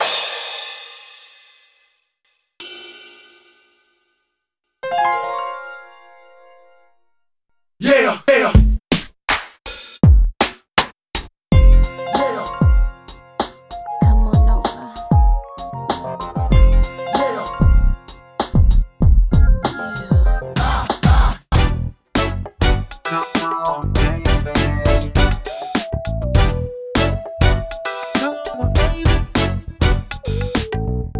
Roland SP-404 MK1 factory samples
icom-ingredients-OG-SP-404-factory-samples.mp3